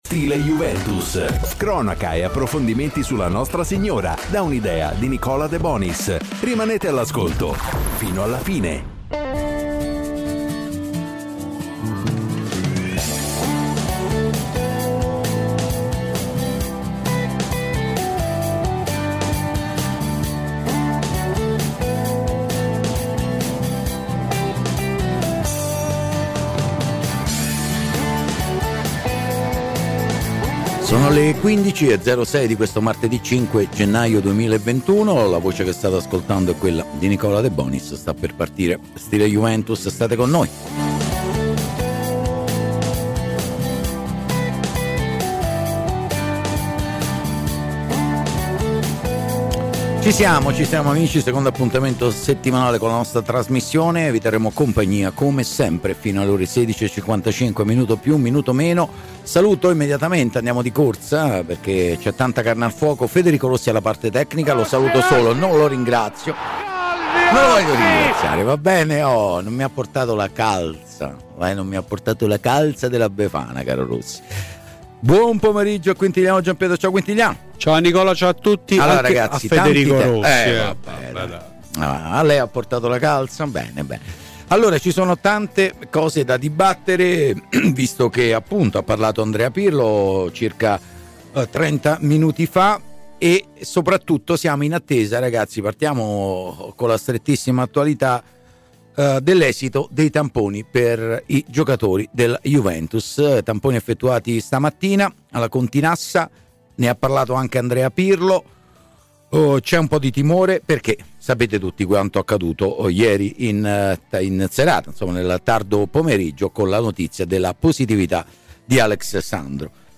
Ai microfoni di Radio Bianconera, nel corso di ‘Stile Juventus’, è intervenuto l’ex bianconero Roberto Galia: “Quagliarella alla Juve?